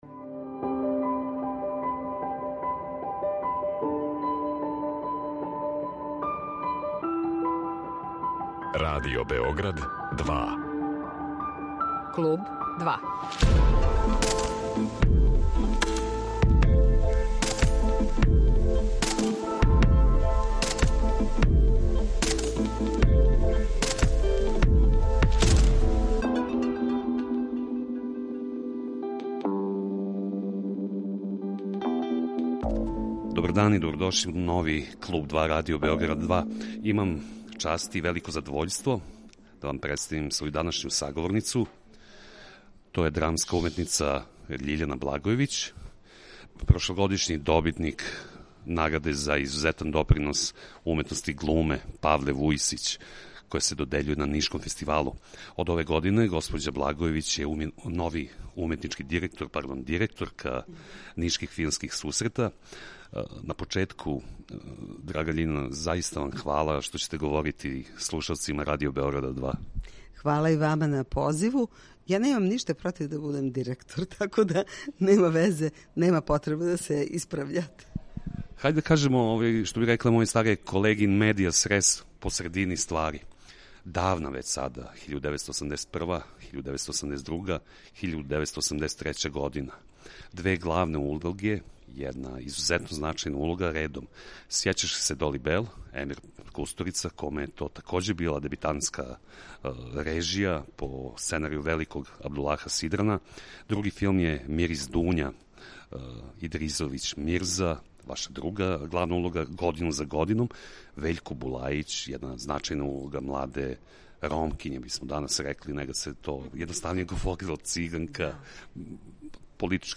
Директно са „Филмских сусрета" у Нишу говориће о својим глумачким почецима, о позоришној каријери, о креирању запажених улога на филму, али и о специфичностима радио-глуме и бројним улогама оствареним у Драмском програму Радио Београда.